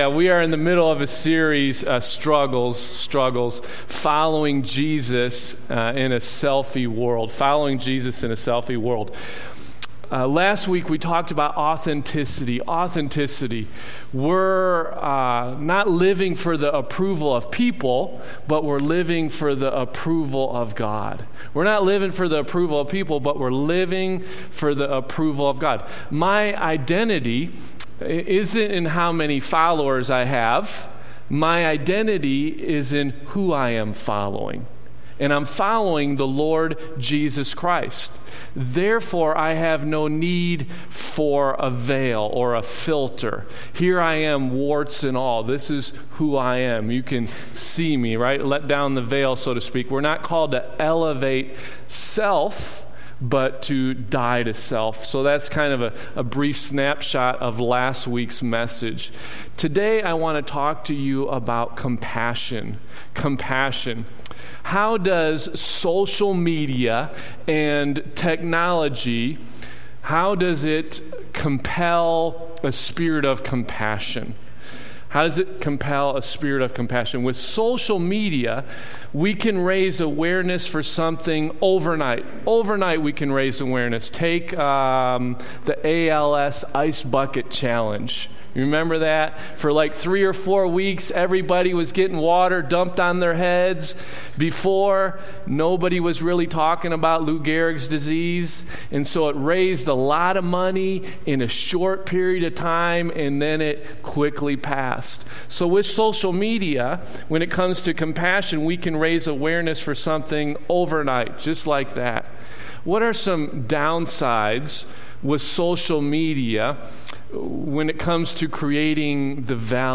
Stone Ridge Community Church Sermon Audio Library